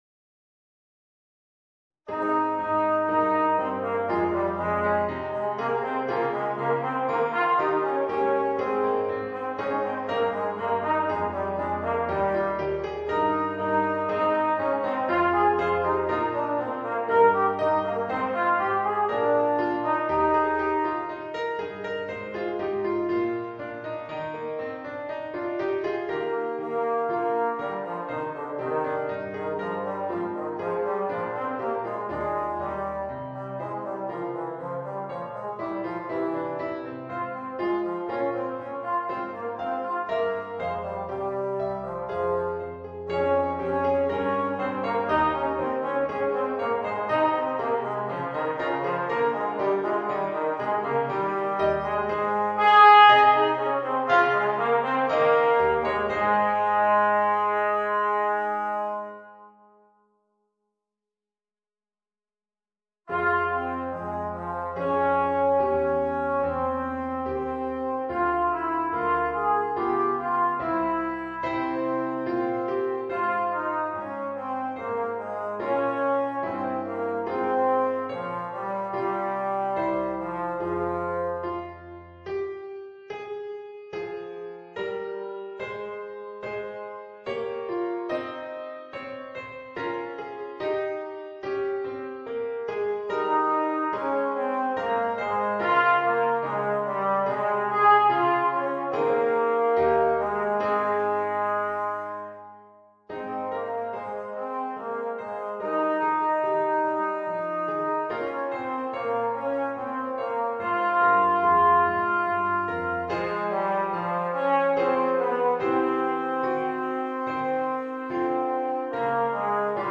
Voicing: Alto Trombone